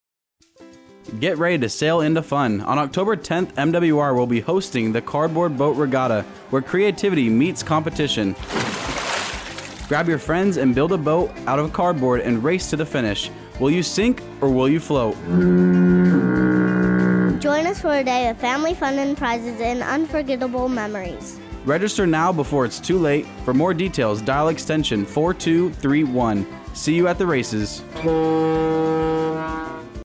Thirty-second spot highlighting the Cardboard Boat Regatta to be aired on AFN Bahrain's morning and afternoon radio show.
Radio SpotAudio SpotMWR BahrainAFN Bahrain